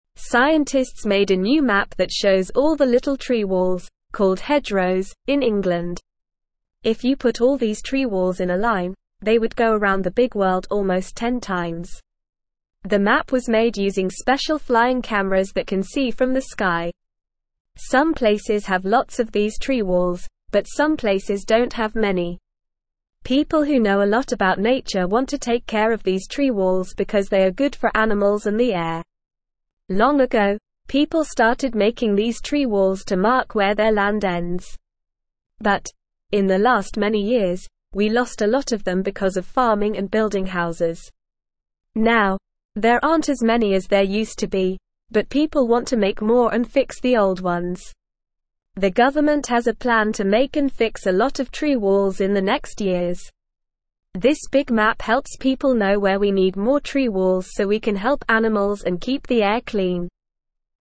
Normal
English-Newsroom-Beginner-NORMAL-Reading-New-Map-Shows-Tree-Walls-in-England.mp3